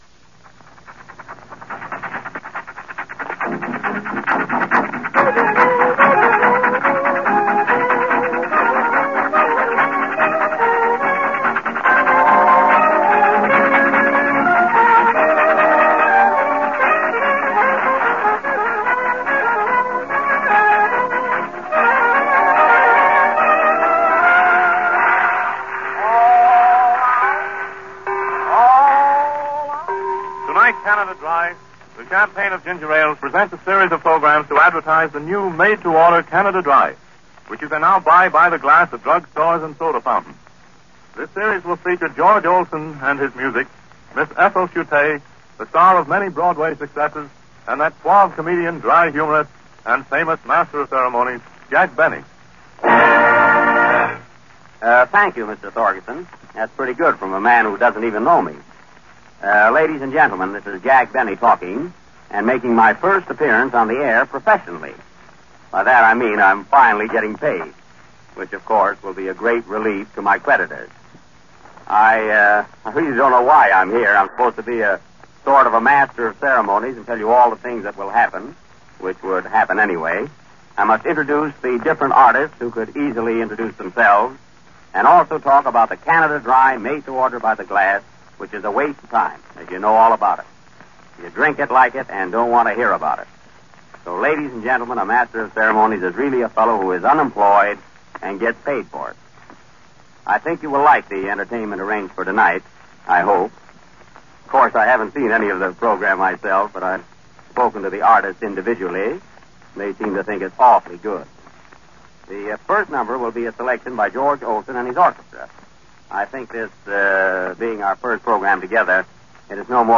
A regular in vaudeville comedy, Jack Benny made his first professional on air appearance in May 2, 1932 on the Canada Dry Program . A different Jack Benny than you know and love, hear Jack Benny as MC in this early 1932 broadcast...